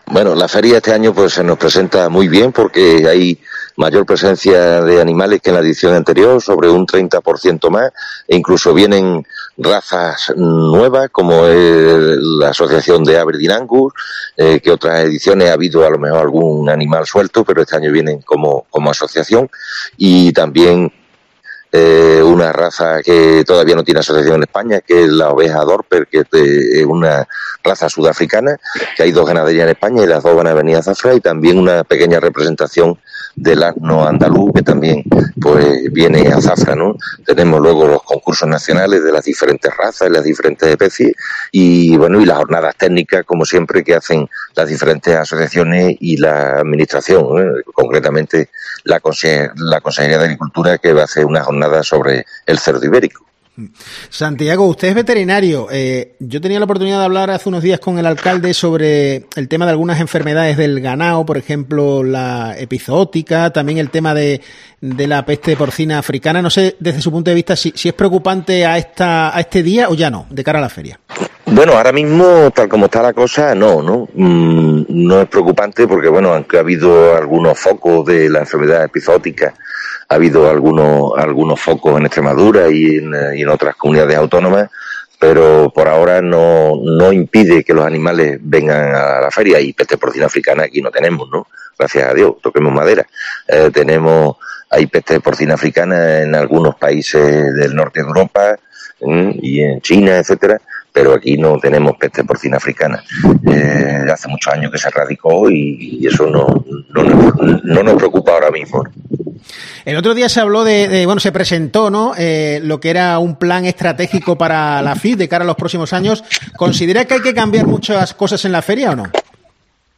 En COPE, hemos hablado con él sobre la edición de este año, que se va a celebrar del 28 de este mes al 3 de octubre.